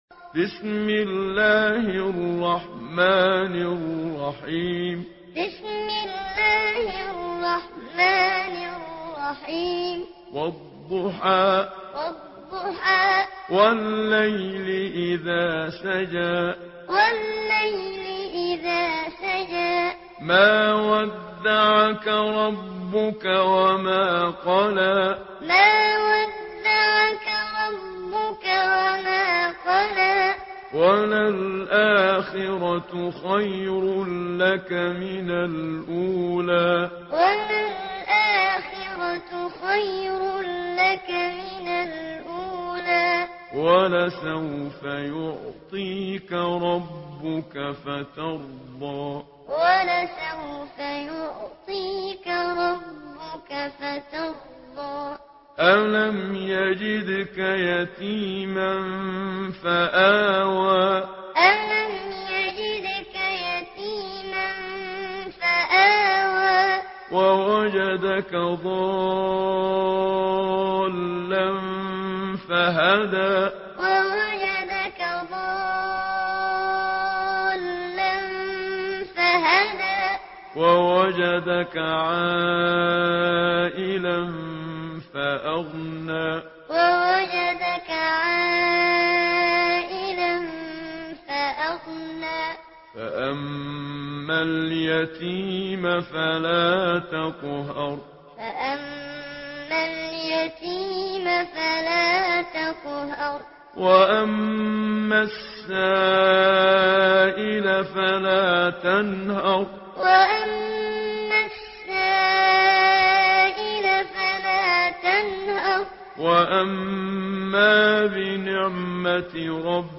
Surah আদ্ব-দ্বুহা MP3 by Muhammad Siddiq Minshawi Muallim in Hafs An Asim narration.